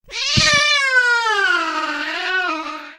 black_cat_dies.ogg